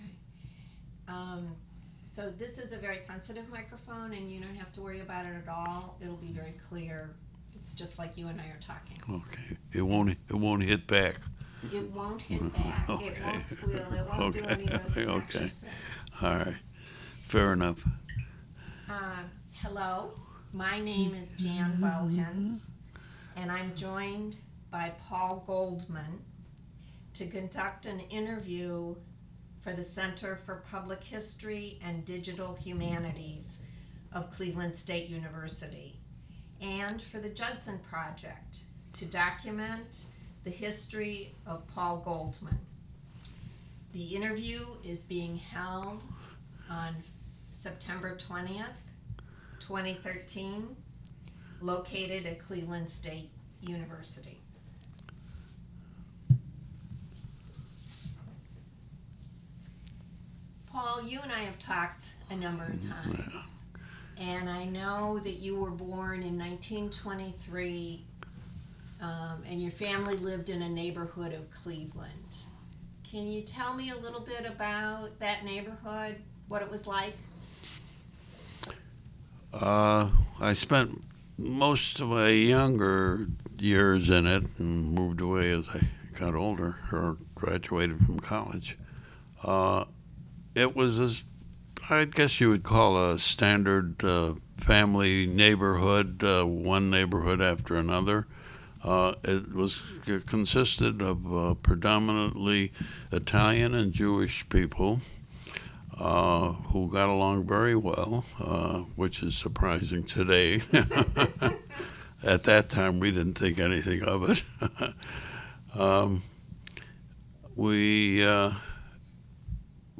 The Cleveland Regional Oral History Collection consists of audio interviews collected beginning in 2002.